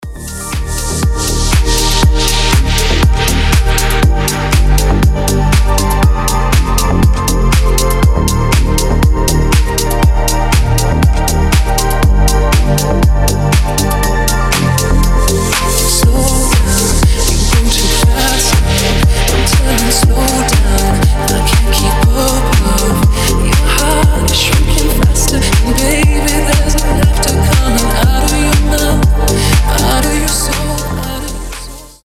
• Качество: 320, Stereo
deep house
мелодичные
женский голос
чувственные
нежные